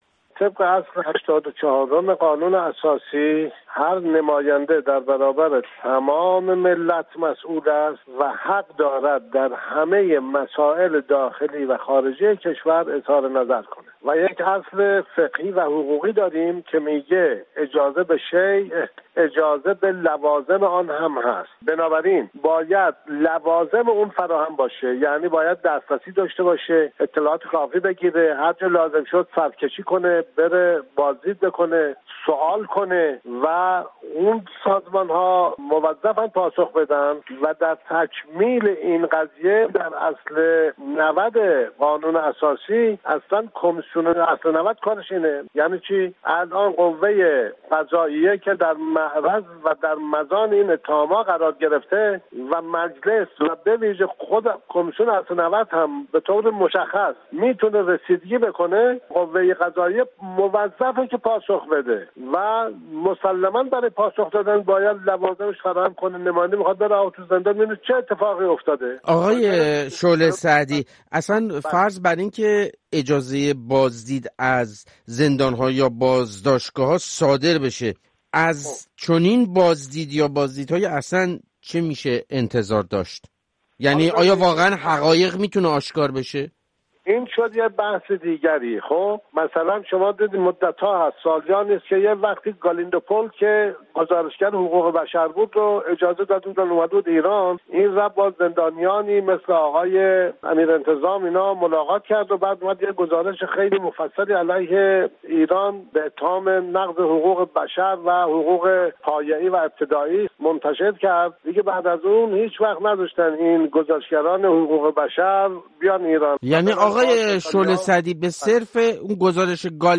«قاسم شعله سعدی» نماینده اسبق مجلس شورای اسلامی که همچنین از وکلای دادگستری ایران و نیز از کوشندگان حقوق بشر به‌شمار می‌رود در گفت‌وگو با رادیو فردا با استناد به قانون اساسی، بازدید نمایندگان مجلس از بازداشتگاه‌ها و زندان‌ها را در حیطۀ وظایف قانونی آنها می‌داند.